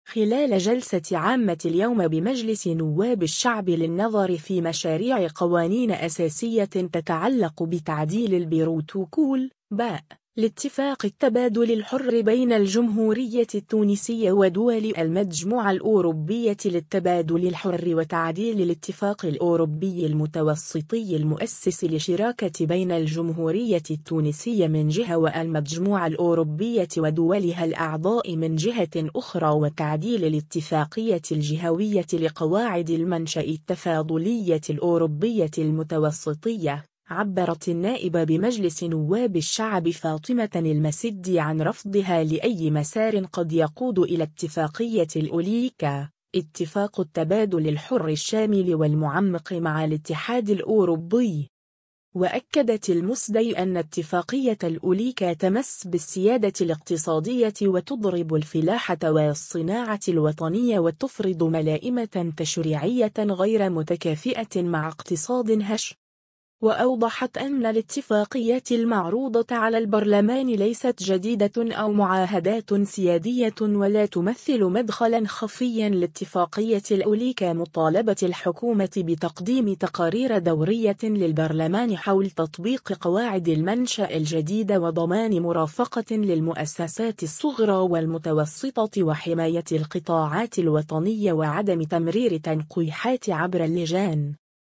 خلال جلسة عامة: النائب فاطمة المسدي تنتقد اتفاقية الأليكا (فيديو)